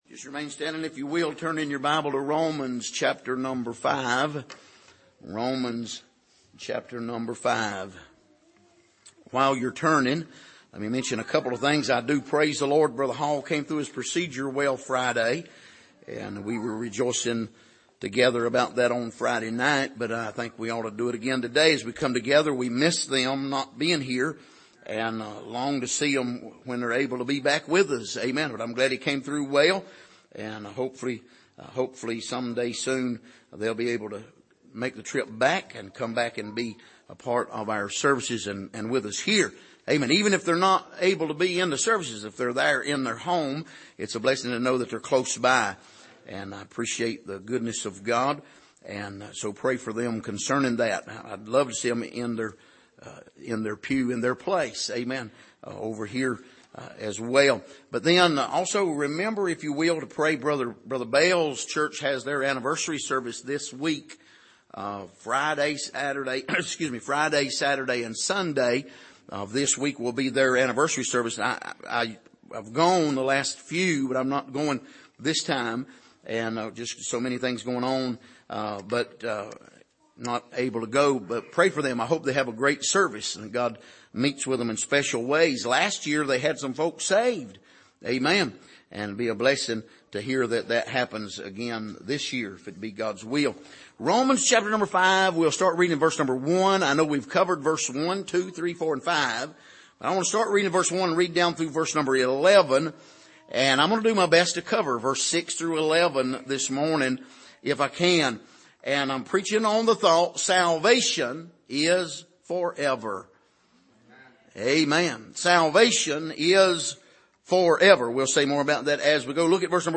Passage: Romans 5:1-11 Service: Sunday Morning